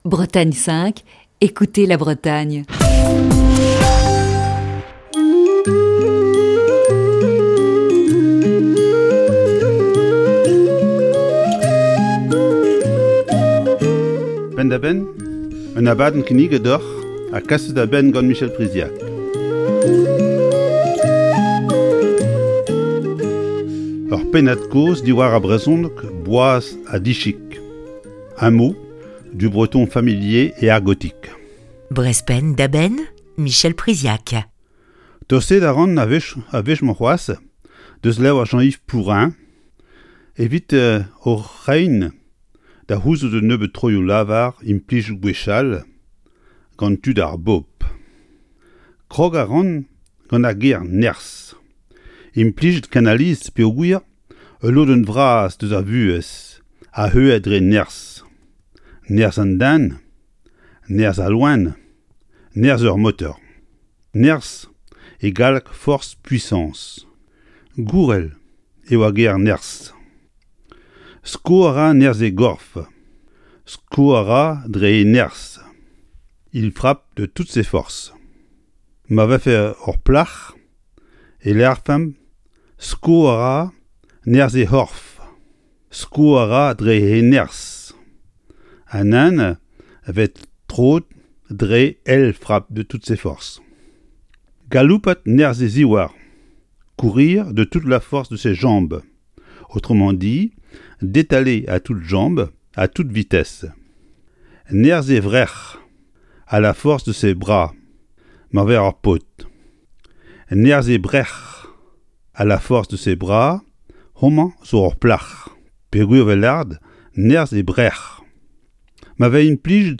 Chronique du 19 novembre 2020.